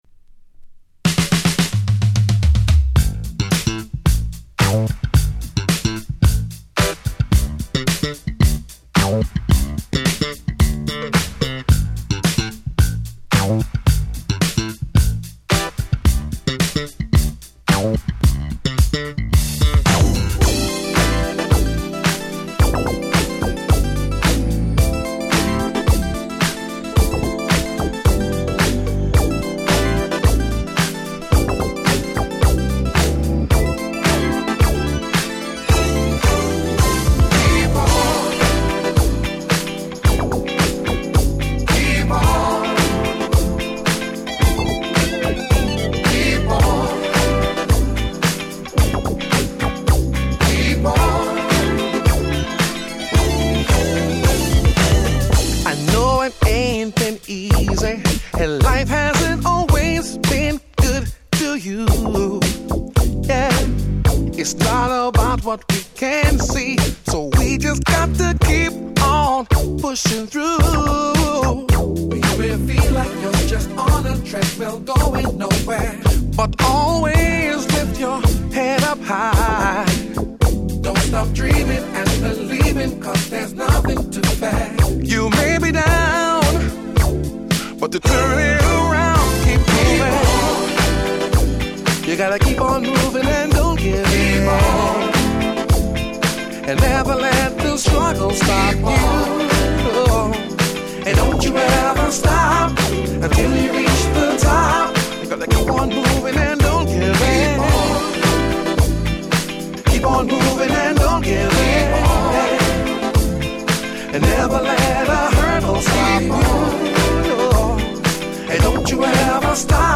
【Media】Vinyl 2LP
※新品未開封！！(試聴ファイルは別の盤から録音してあります。)